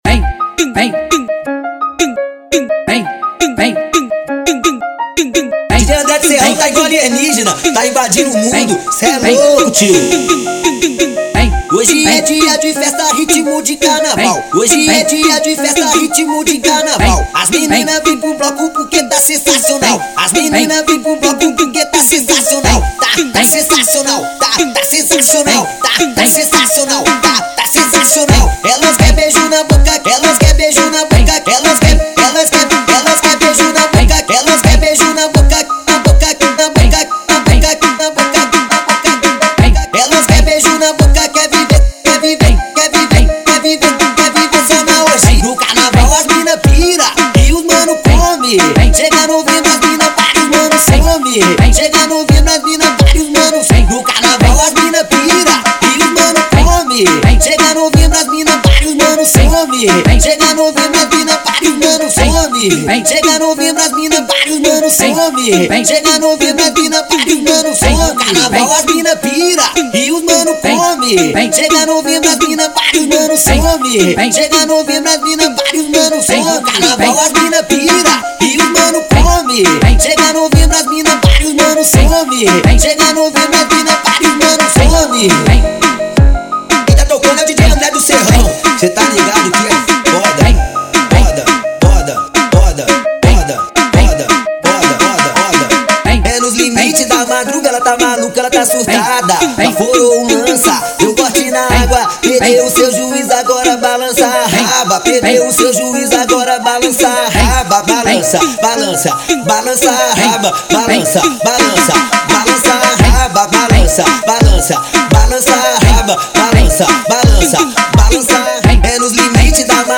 EstiloFunk